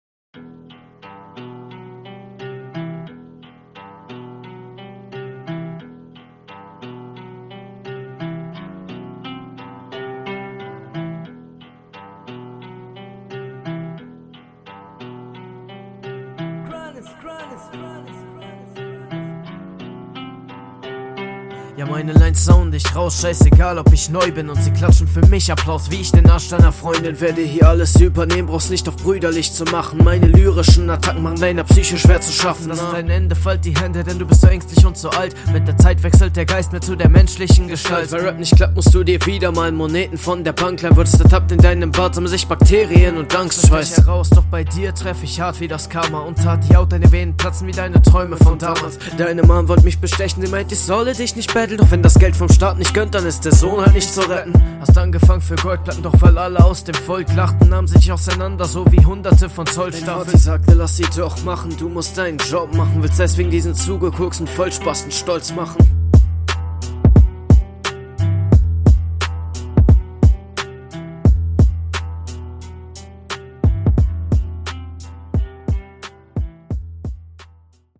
Beat is ganz geil, wird deinem Gegner aber in die Karten spielen!
Servus, Beat gefällt mir schon mal ganz gut, schön dirty.